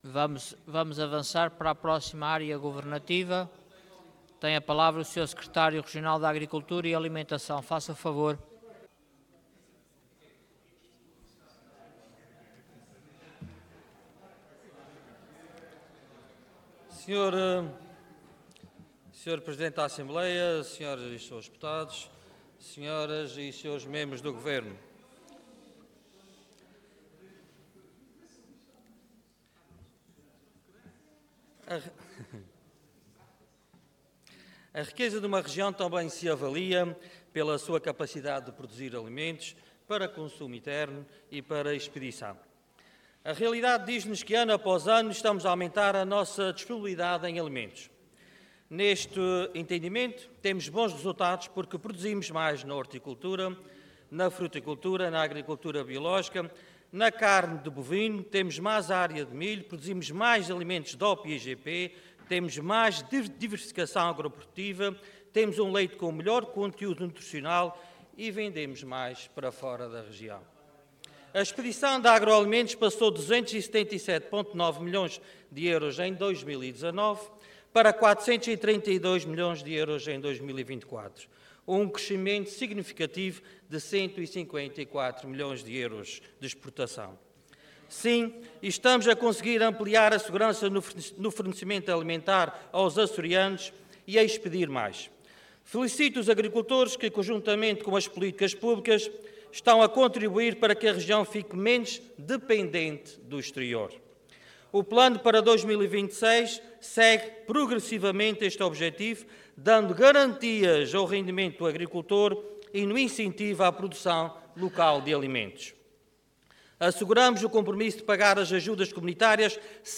Intervenção de Tribuna Orador António Ventura Cargo Secretário Regional da Agricultura e Alimentação